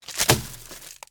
trashcan2.ogg